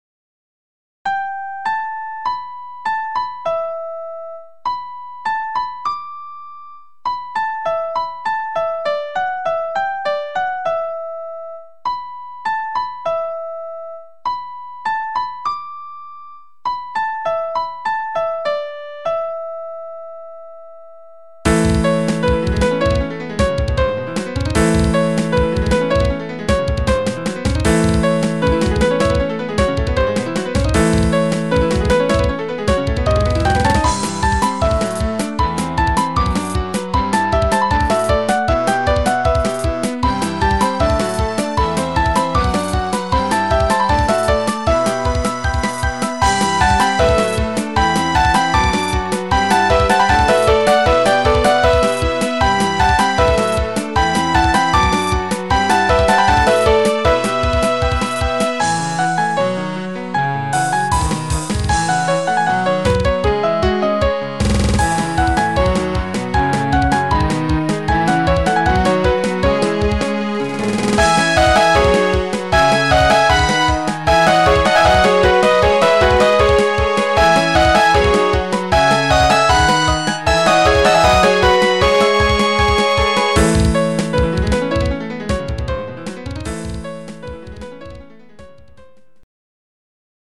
一応音量面とか気にかけてみたけど　微妙